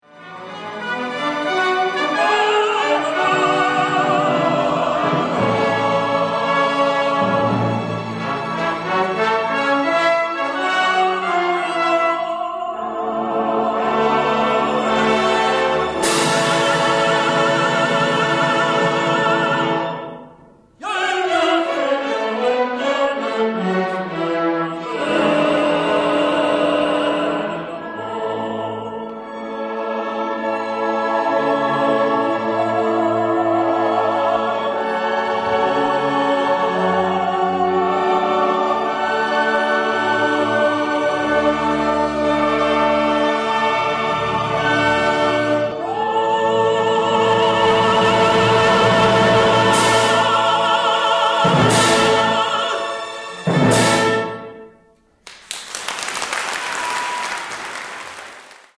Enregistrement live.
soprano
ténor